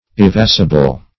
\e*va"si*ble\ ([-e]*v[=a]"s[i^]*b'l)